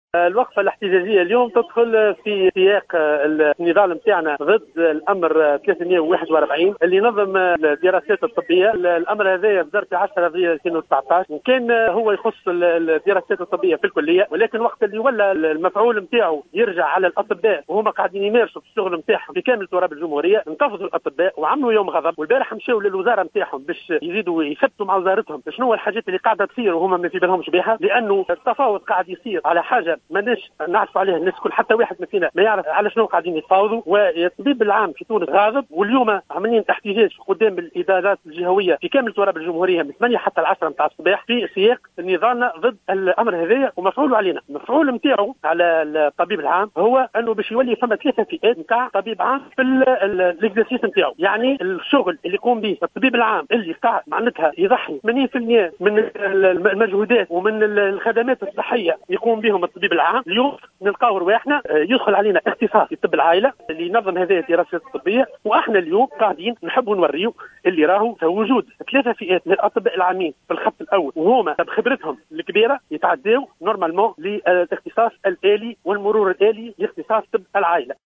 نفذ اليوم عدد من الأطباء العامين في القطاعين العام والخاص وقفة إحتجاجية أمام مقر الإدارة الجهوية للصحة بسوسة.